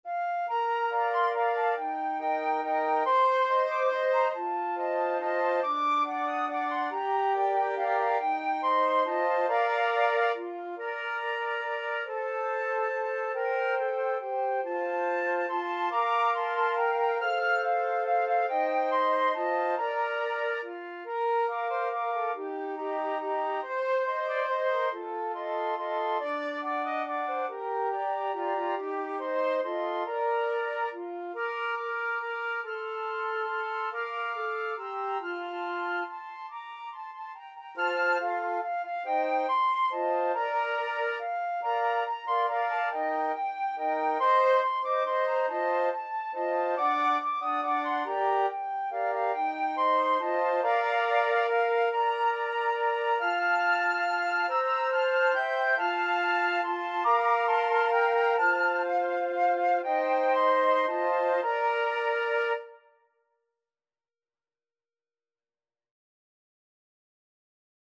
Bb major (Sounding Pitch) (View more Bb major Music for Flute Quartet )
3/4 (View more 3/4 Music)
Allegro = 140 (View more music marked Allegro)
Flute Quartet  (View more Easy Flute Quartet Music)
Traditional (View more Traditional Flute Quartet Music)